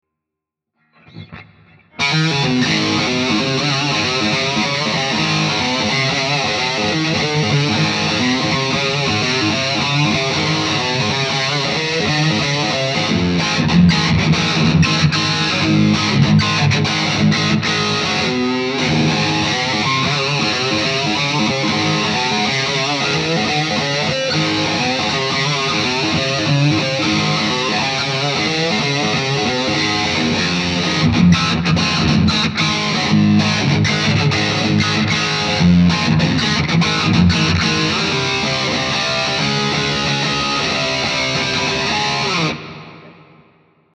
Tuning = Eb, Ab, Db, Gb, Bb, Eb,
original bang-around "noodle" which was the inspiration and idea I had for this lesson's song track.
Take note that some of the riff is the same, and some of it isn't.  In fact the main theme riff of this track started out as just a very simple chord pattern.